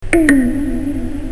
cartoon41.mp3